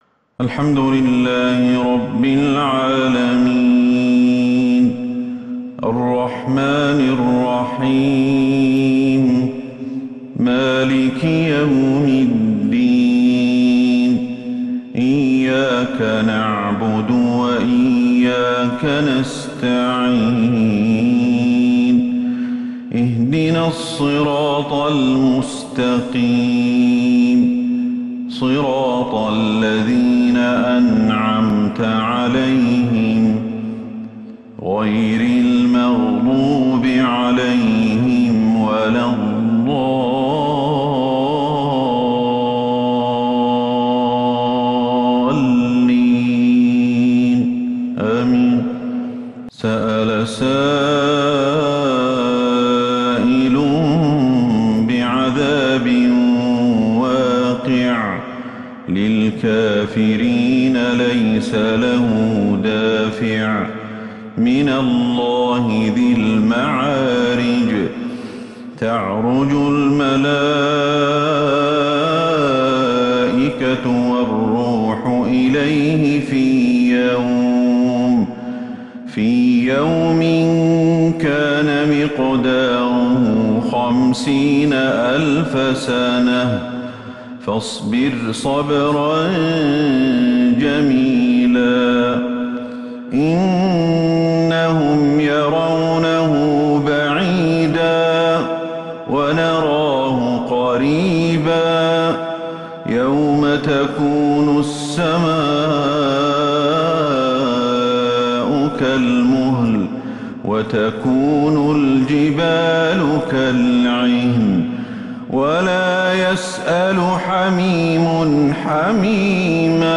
عشاء الجمعة 9 ربيع الأول 1443هـ سورة {المعارج} > 1443 هـ > الفروض - تلاوات الشيخ أحمد الحذيفي